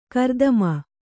♪ kardama